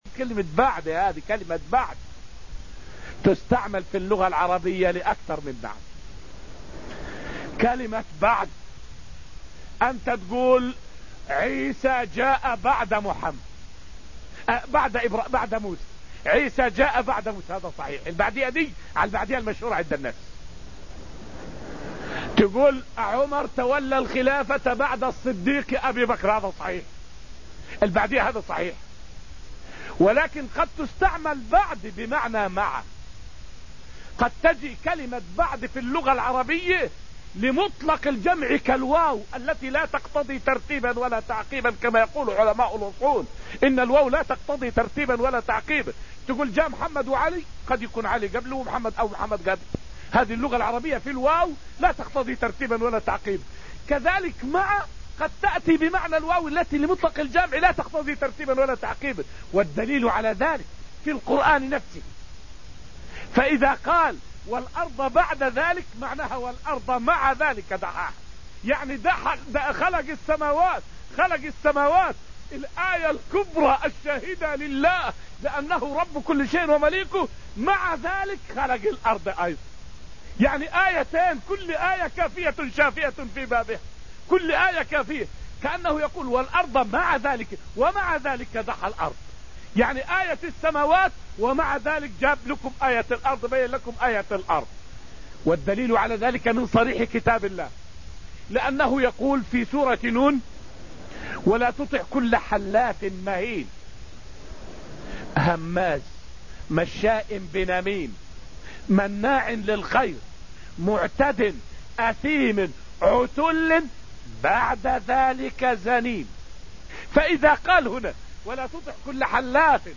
فائدة من الدرس الثالث من دروس تفسير سورة الحديد والتي ألقيت في المسجد النبوي الشريف حول معنى قوله تعالى: {والأرض بعد ذلك دحاها}.